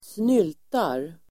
Uttal: [²sn'yl:tar]